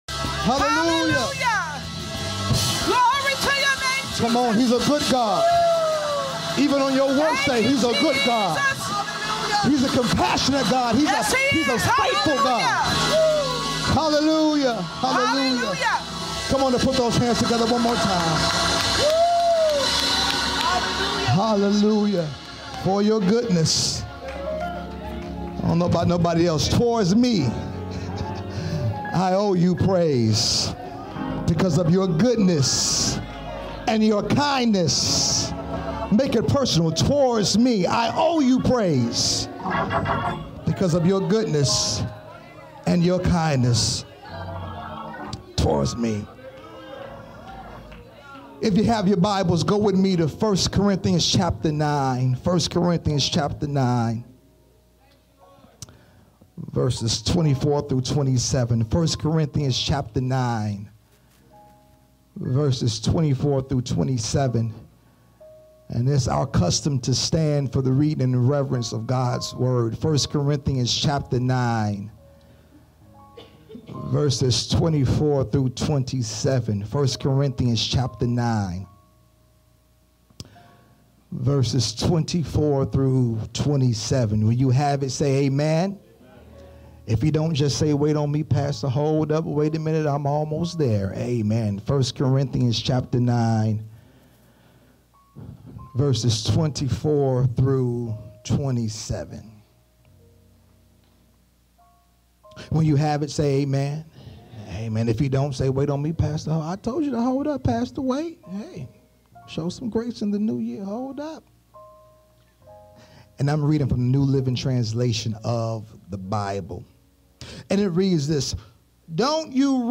Sermons - Hopewellmb